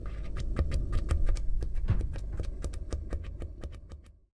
magma.ogg